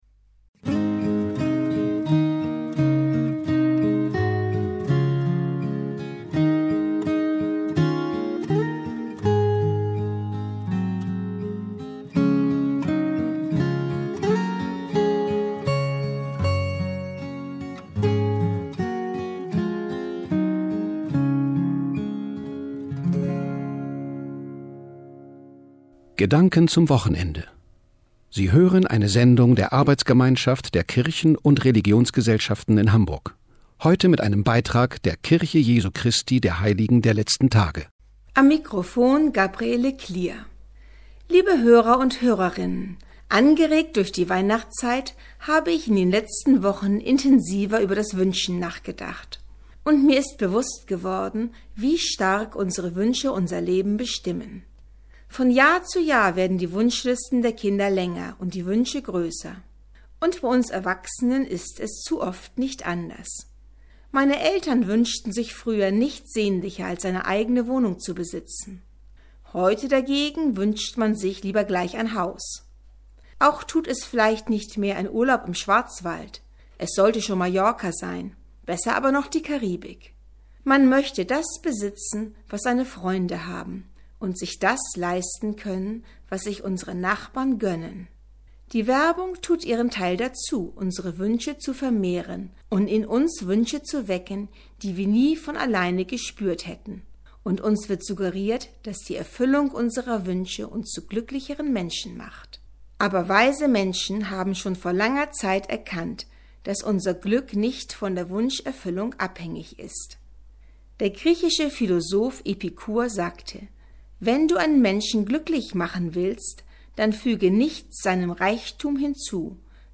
Radiobotschaft zum Thema "Wünschen" jetzt als Podcast auf der Presseseite
Die am Samstag, den 15. Dezember 2012, auf dem Hamburger Bürger- und Ausbildungskanal TIDE gesendete Botschaft der Kirche Jesu Christi der Heiligen der Letzten Tage steht ab sofort als Podcast auf der Presseseite zur Verfügung.